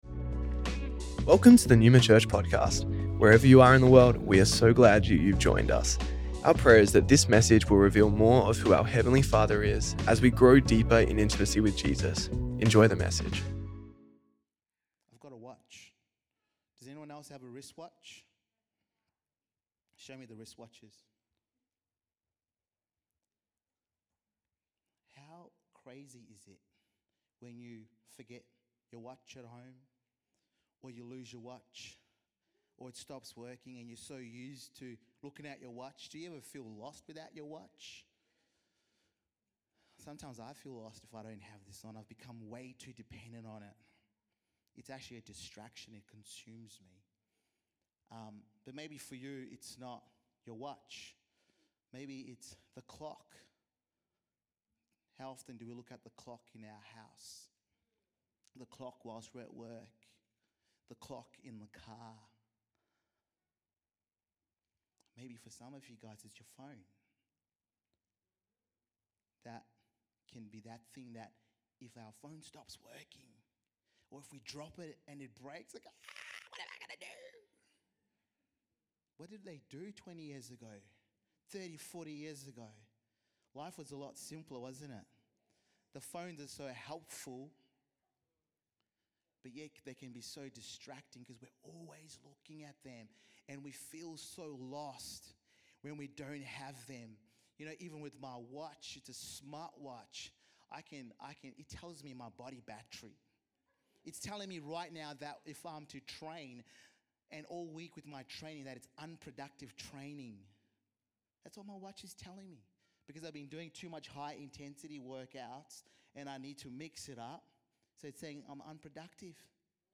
Neuma Church Melbourne South Originally recorded at the 10AM Service on Sunday 31st August 2025